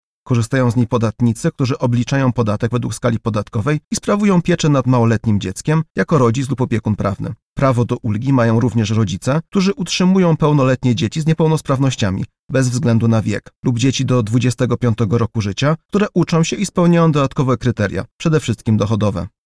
Ulga na dzieci jest najpopularniejszą ulgą podatkową. Rzecznik odpowiada na pytanie, kto może skorzystać ze wspomnianej ulgi: